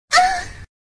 villager / hit3.ogg
hit3.ogg